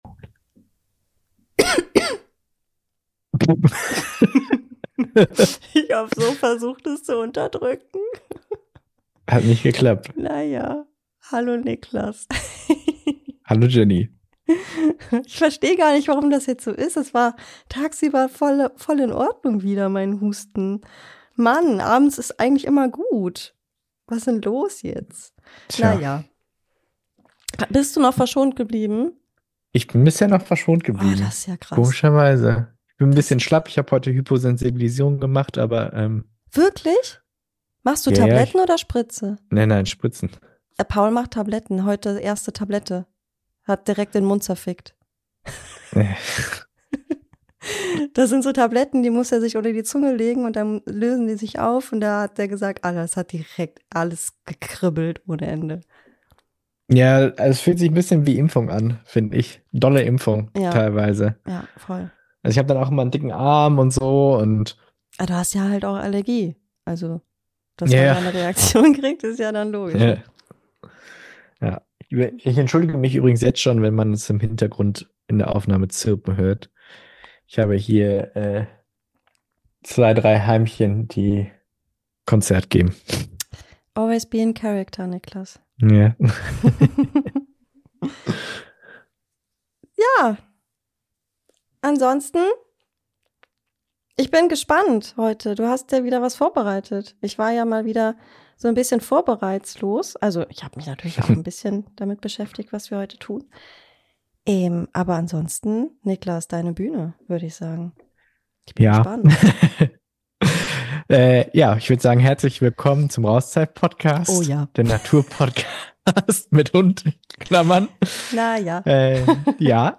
Und direkt vorweg: Ich war zu diesem Zeitpunkt immer noch angeschlagen von zwei Wochen Grippe, und das hört man leider auch.